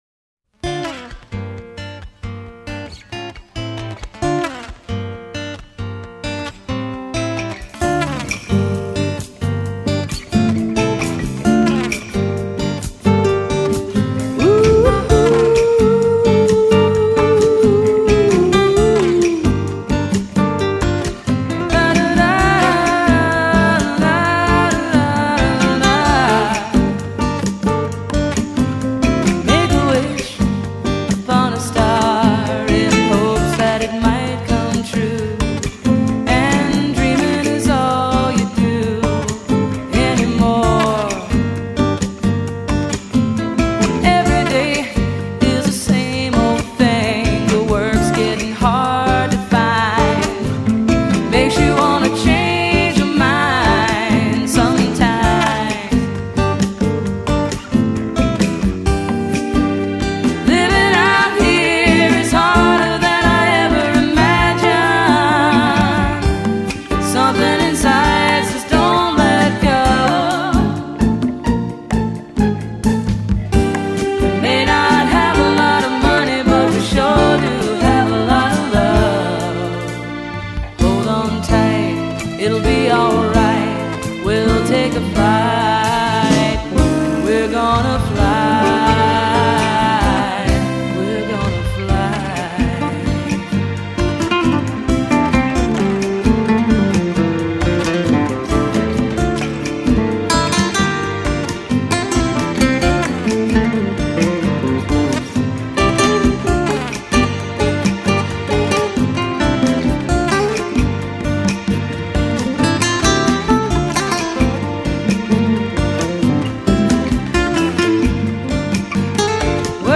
Band Recording Session
vocals, guitar
Vocals, percussion
Classical guitar, keyboards